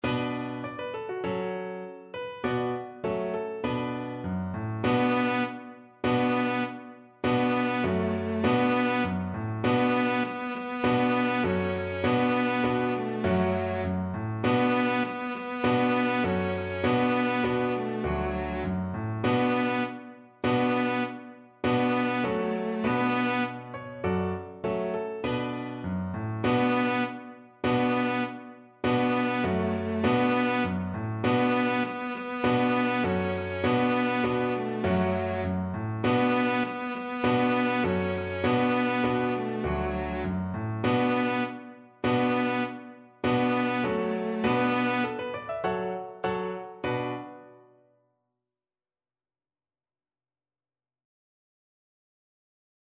Cello
B minor (Sounding Pitch) (View more B minor Music for Cello )
Firmly =c.100
2/4 (View more 2/4 Music)
Traditional (View more Traditional Cello Music)